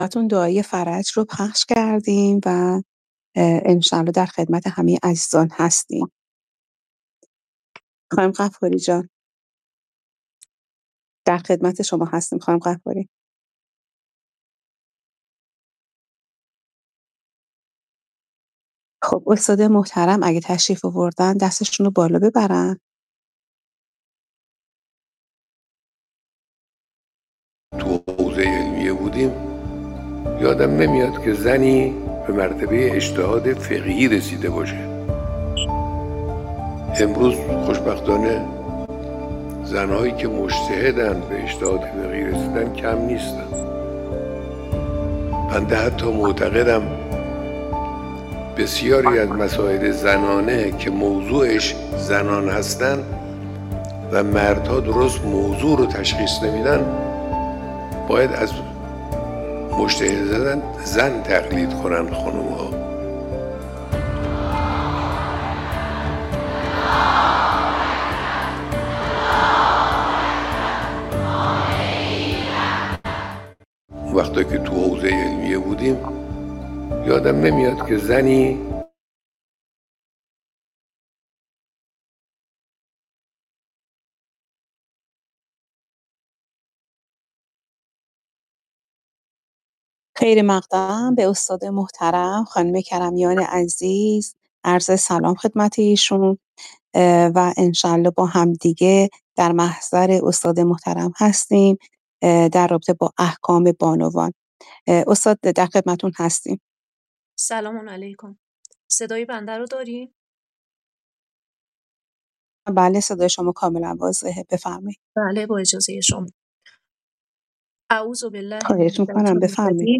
بانوی تراز (احکام بانوان) - جلسه-پرسش-و-پاسخ